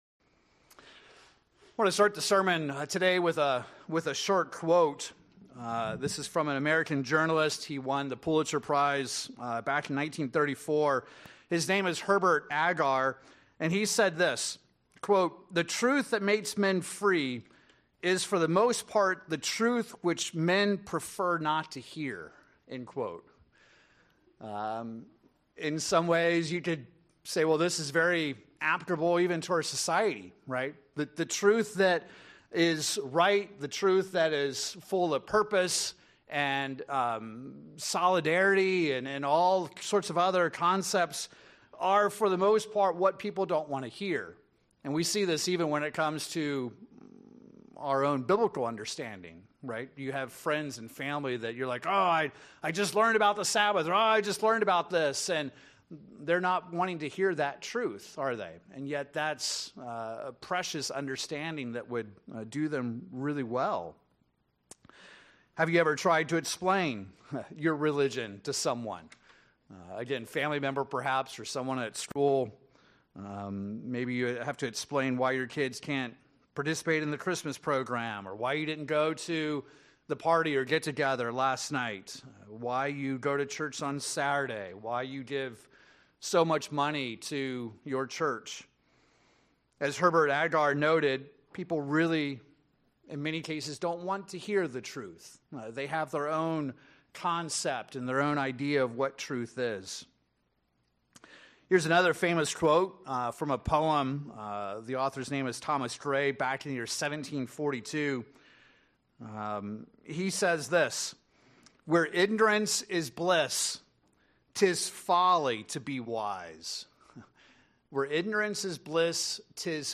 In this sermon, we examine what is godly truth.